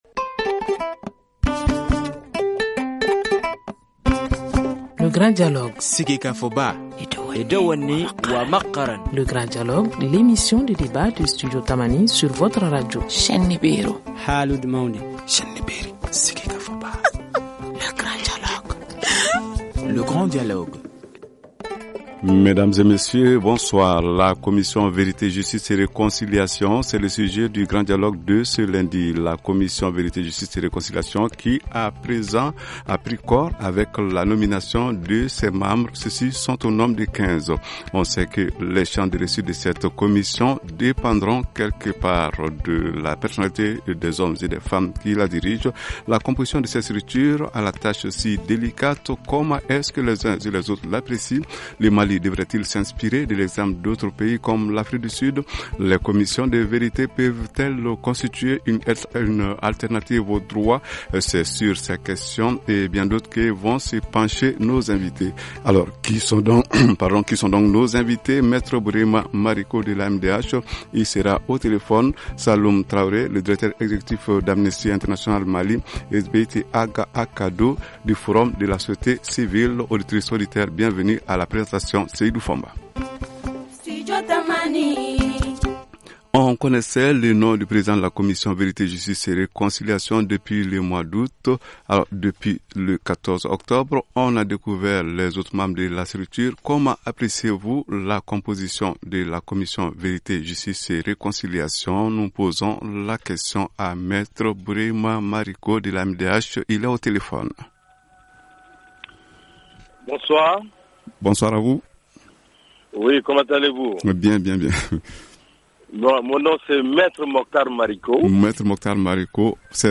Dans quelles mesures le Mali peut-il s’inspirer de l’exemple de l’Afrique du Sud cité comme une réussite ? C’est à ces questions et à bien d’autres que vont tenter de répondre nos invités.